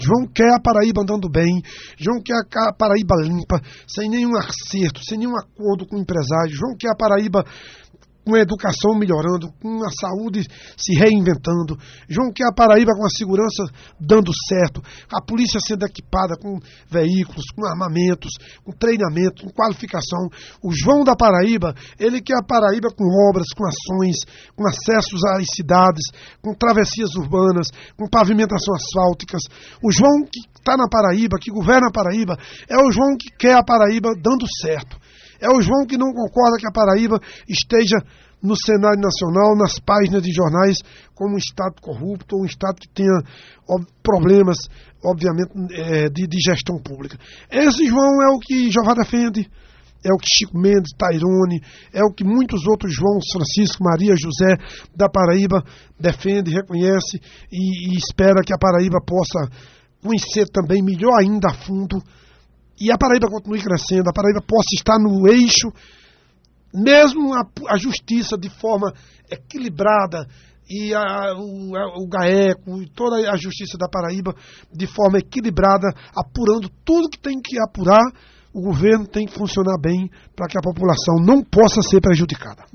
O prefeito de São José de Piranhas, Chico Mendes (PSB), em recente entrevista ao Programa Rádio Vivo da Alto Piranhas (08), fez uma avaliação da gestão do governador João Azevedo (sem partido), que a Paraíba continua no caminho certo com as obras que estão em andamento na Paraíba a começar do sertão.